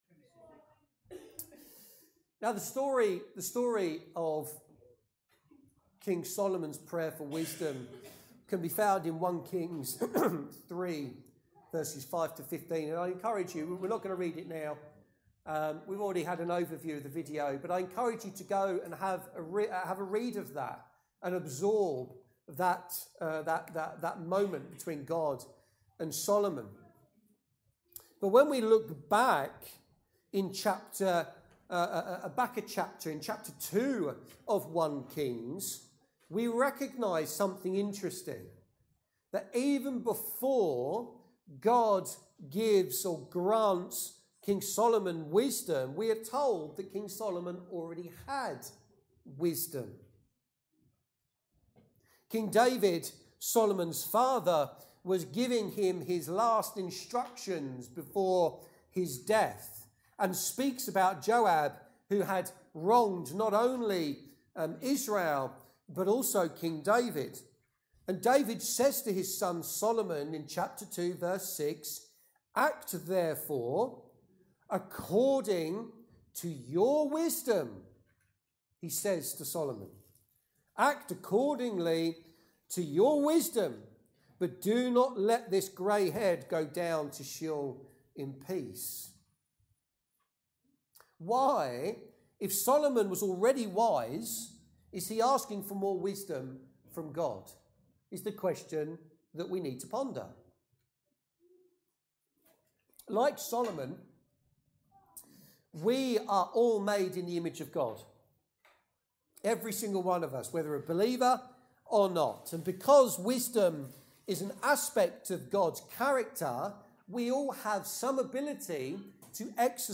Sermons and Talks - Welcome Church